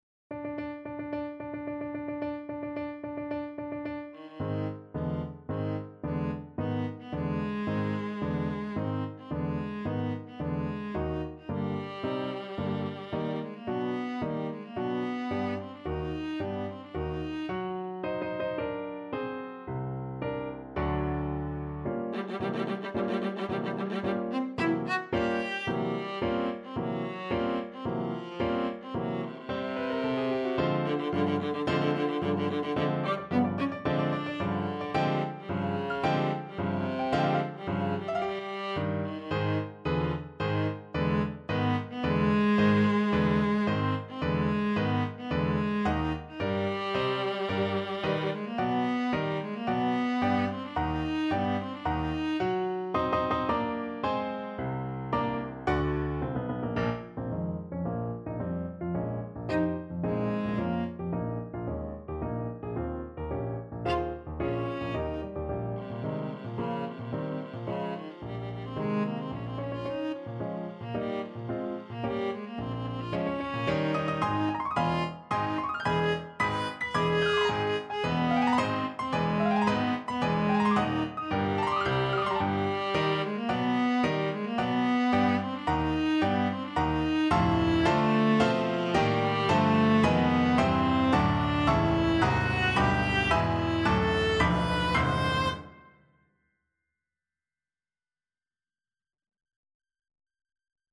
4/4 (View more 4/4 Music)
~ = 110 Tempo di Marcia
Classical (View more Classical Viola Music)